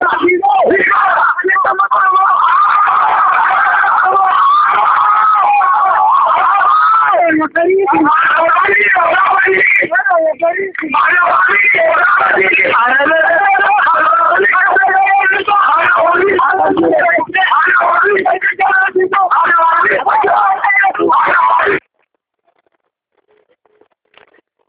Halkan edaa sa’aa 12:30 wb irraa eegaluun hanga halkan keessa sa’a 5:00tti Mooraa Yuunibarsiitii Dirree Dhawaa dhuunfachuun walleewwaan warraaqsaa ABO fi uumata Oromoo faarsuu fi qabsoo bilisummaa Oromoo leellisuun diddaa jabaa kaasan.
walleelee-waraqsaa.mp3